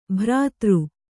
♪ bhrātř